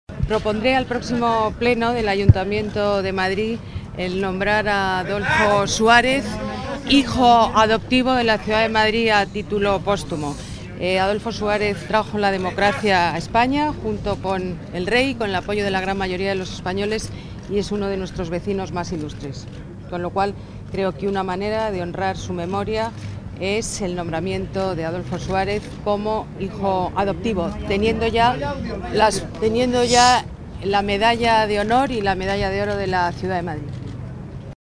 Nueva ventana:Declaraciones de Ana Botella, alcaldesa de Madrid